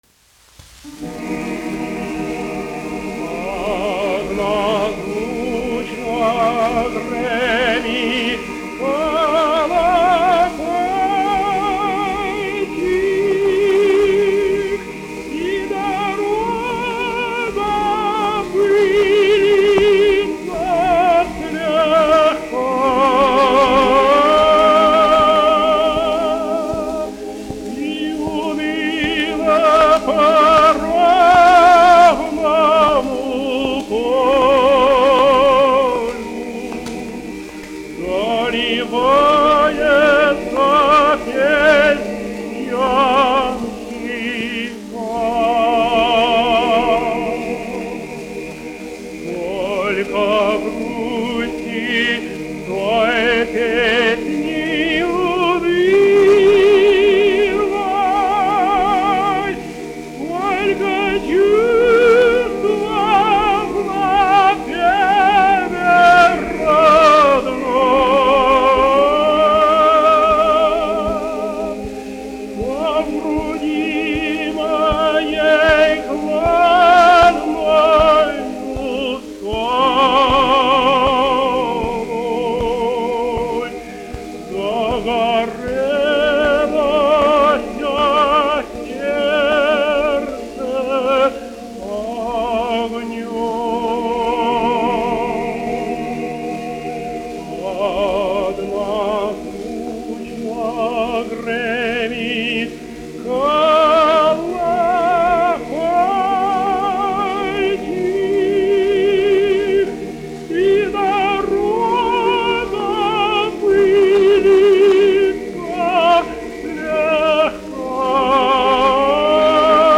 1 skpl. : analogs, 78 apgr/min, mono ; 25 cm
Krievu tautasdziesmas
Latvijas vēsturiskie šellaka skaņuplašu ieraksti (Kolekcija)